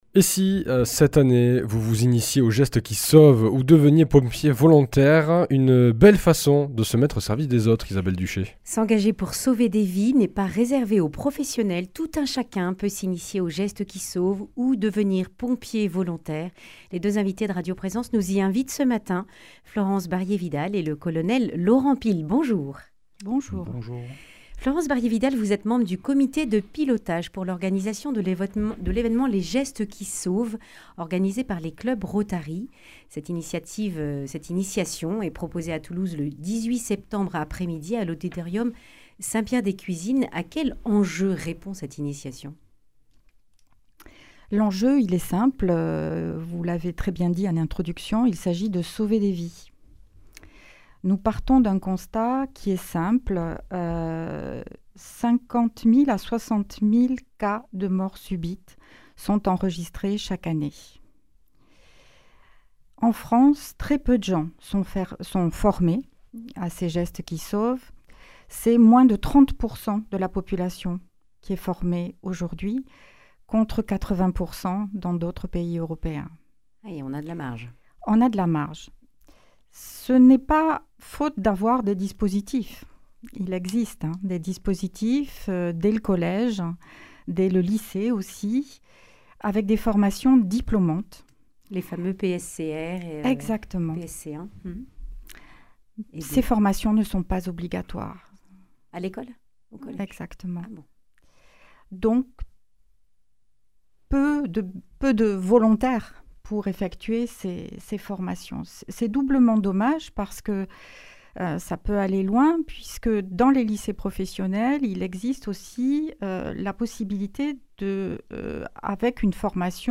Le grand entretien